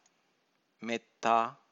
Meththā